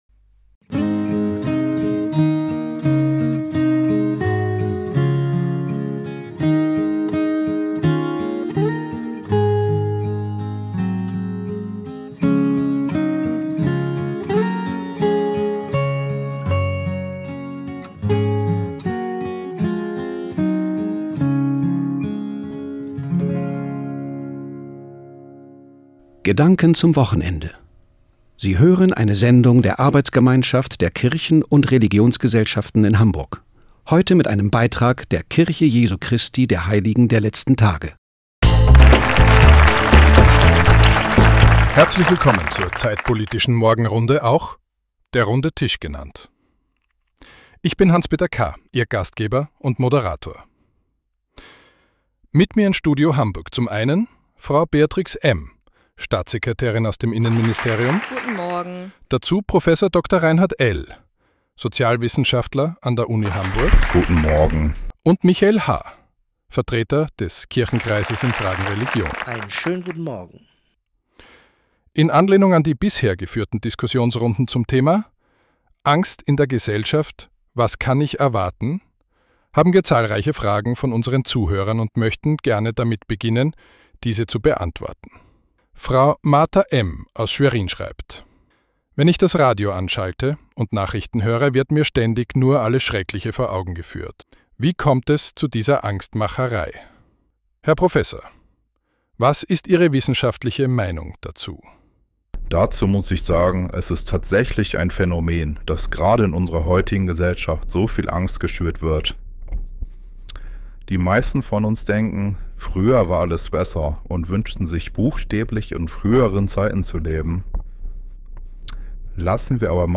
Radiobotschaften vom Mai, Juni und Juli